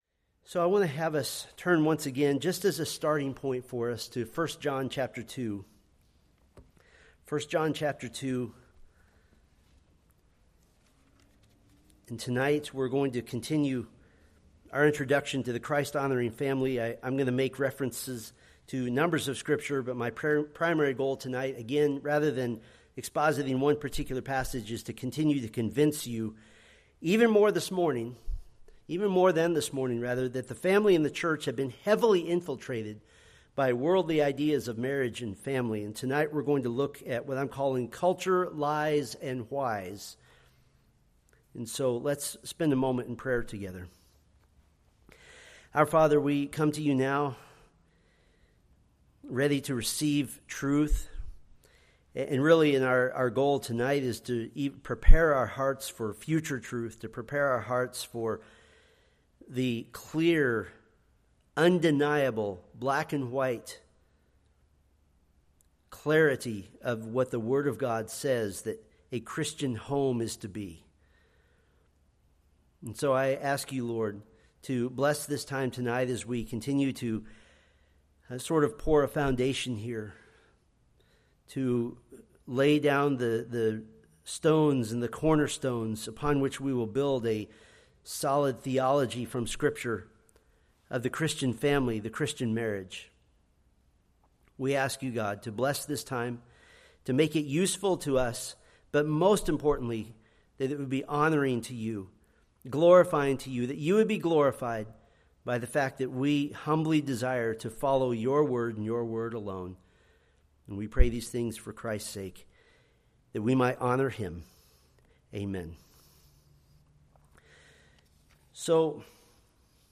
Preached June 29, 2025 from Selected Scriptures